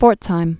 (fôrtshīm, pfôrts-)